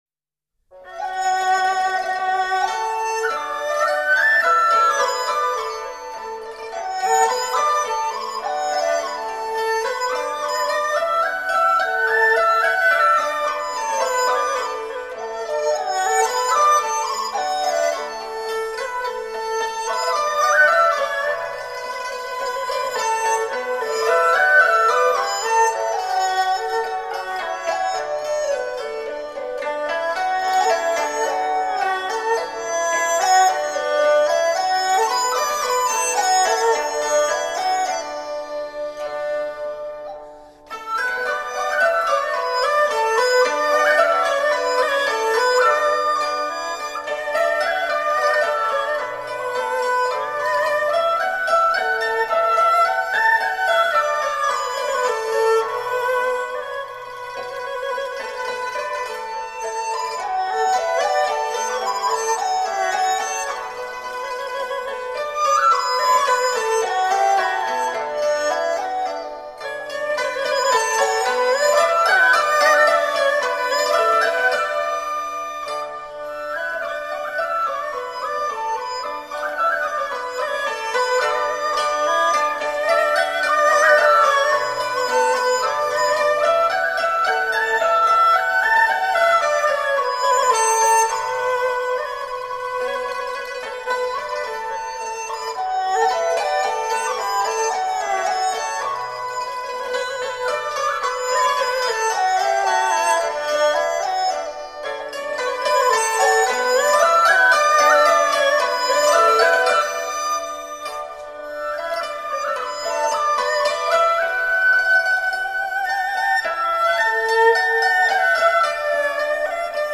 聆听优美、细腻的江南丝竹音乐，领悟传统与经典的永恒时尚！
中央电视台480平米模拟录音棚